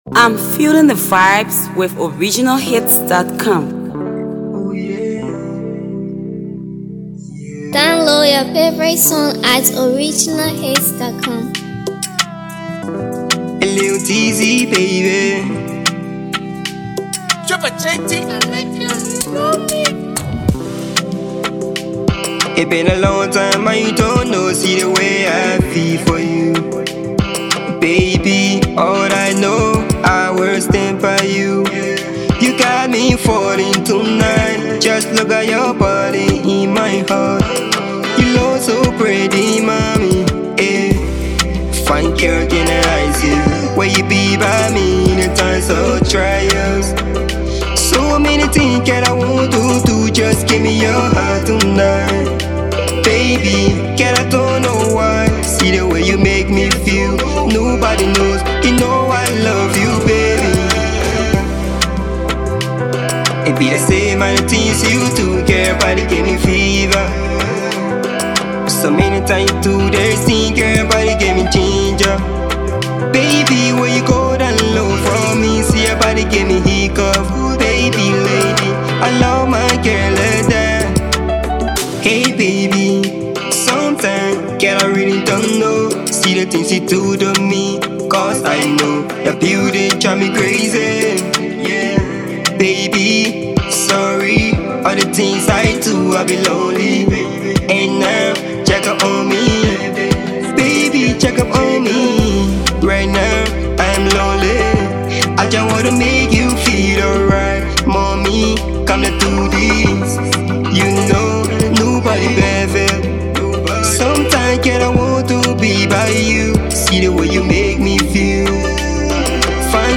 studio effort